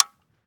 weap_delta_disconnector_plr_01.ogg